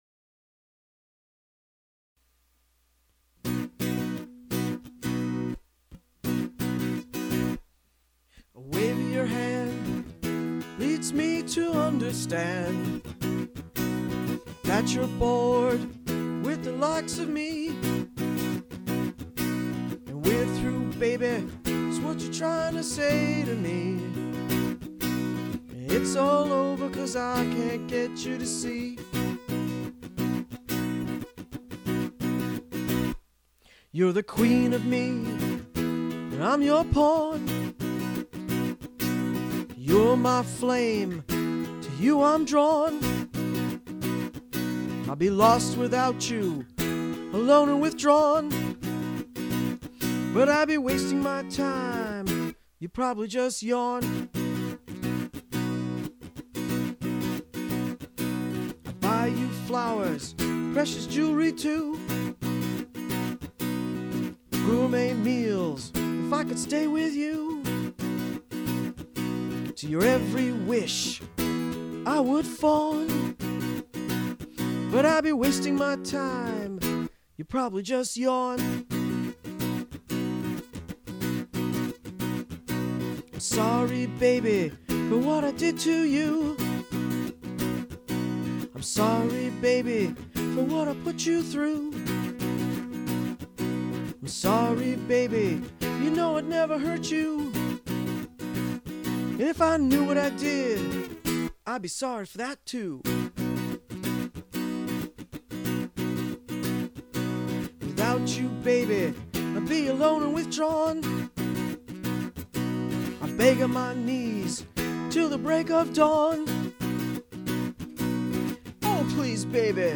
Politically Incorrect Modern Blues